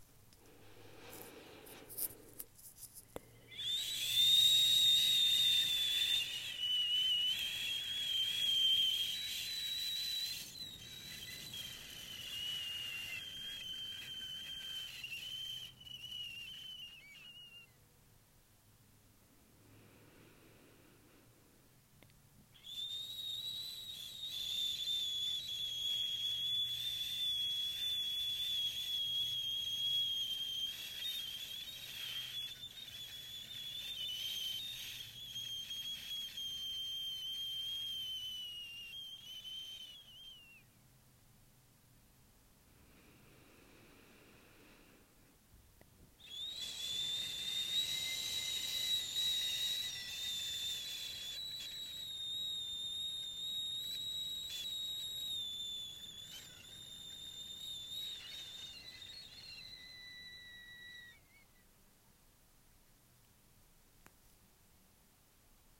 Sixth Sound HSS
Similarity: like the sound of air slowly leaking out of a tire